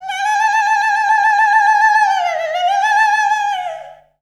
Index of /90_sSampleCDs/Voices_Of_Africa/SinglePhrasesFemale
19_Ee_UndulatingHigh.WAV